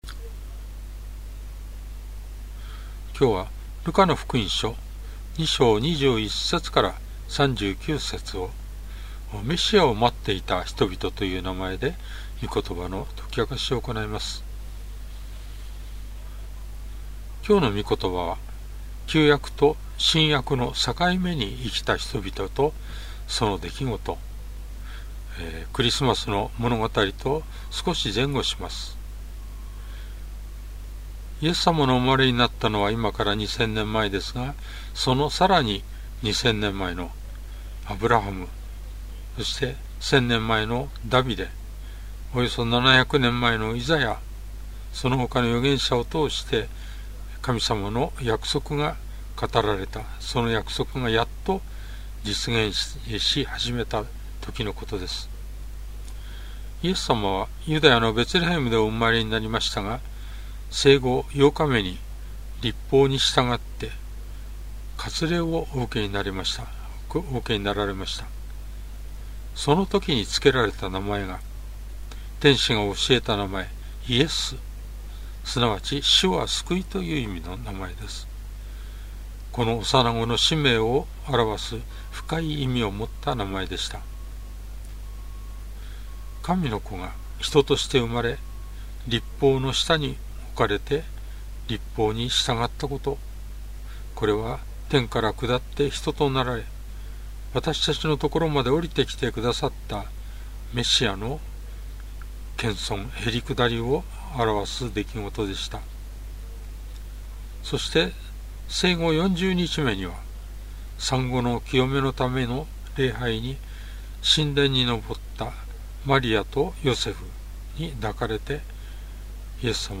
Sermon
主日礼拝
説教